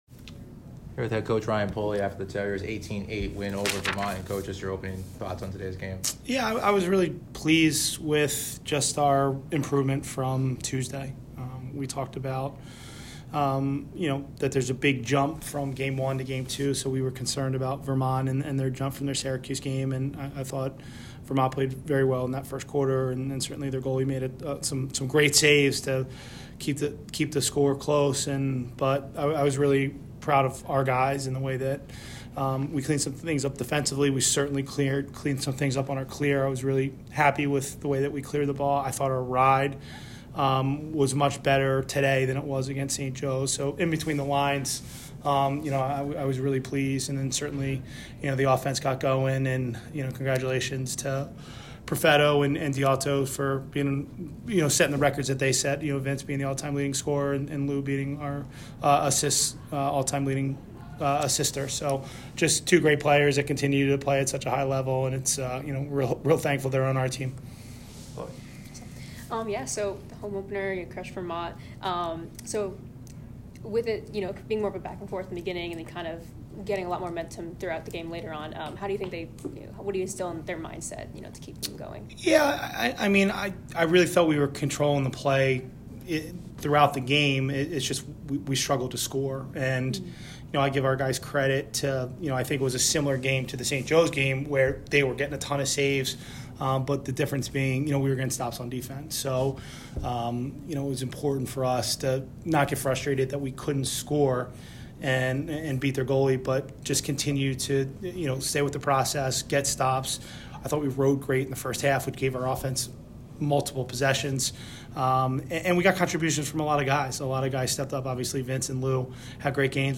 Vermont Postgame Interview